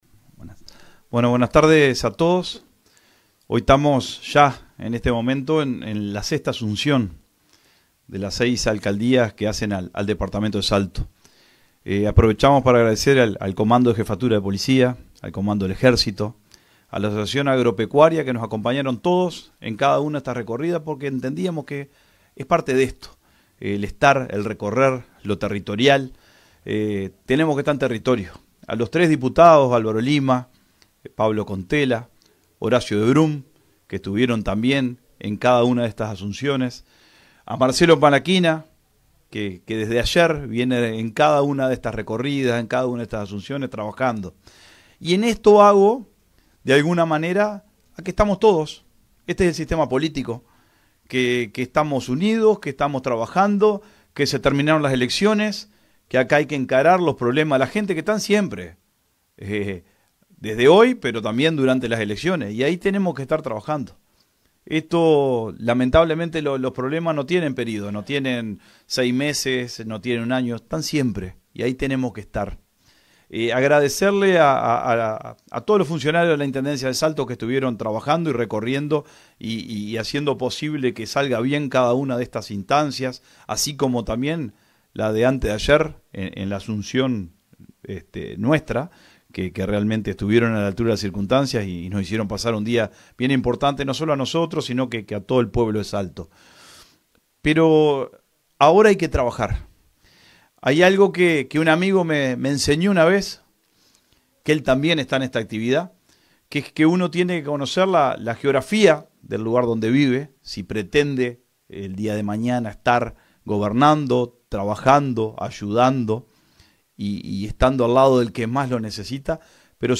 El intendente Carlos Albisu cerró el acto destacando la importancia del trabajo conjunto entre las diferentes instancias del gobierno para abordar los desafíos de Salto y sus municipios.